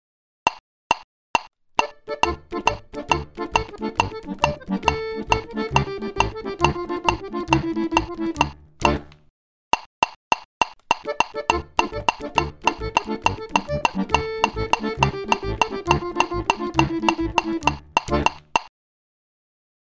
Das hab ich mal kurz nachgebaut: 110bpm, je ein Takt 6/8, 4/4 und dann Achtel in dem 4/4 Takt. Auf die 1 jeweils 1 Kick Dein Browser kann diesen Sound nicht abspielen.